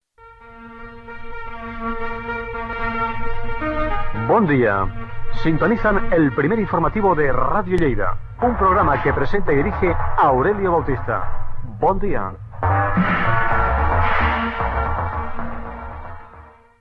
Identificació del programa